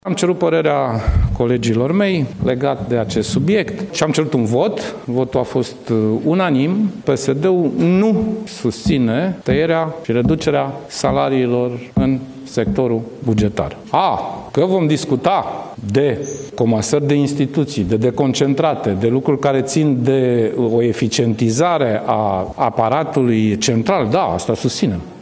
Partidul Social Democrat nu susține tăierea salariilor cu 10% în sectorul bugetar. Declarația vine chiar de la șeful partidului, Sorin Grindeanu, după ședința Biroului Permanent Național al PSD.
Președintele PSD, Sorin Grindeanu: „Am cerut părerea colegilor mei legat de acest subiect și am cerut un vot, care a fost unanim”